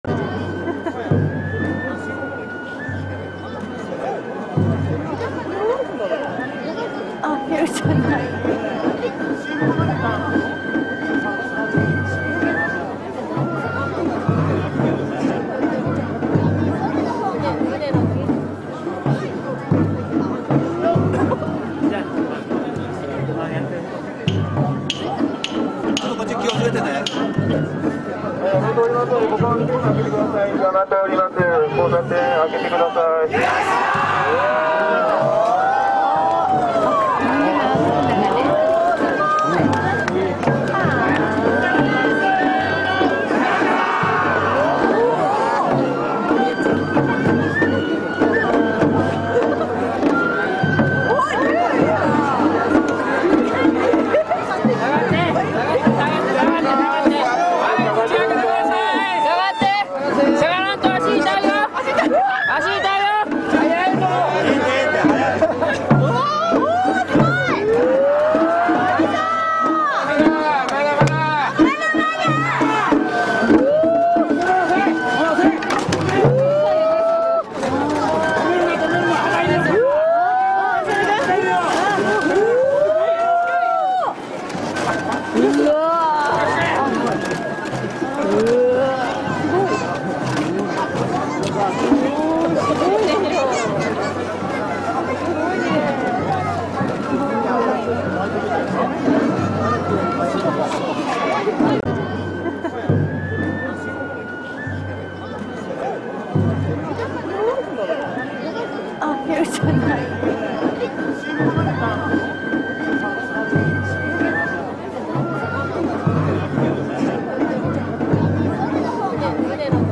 H24犬山祭
車山は全部で13両であるが鍛治屋町の寿老台の1両だけの演技を見るのみとした。
祭賑
祭賑.mp3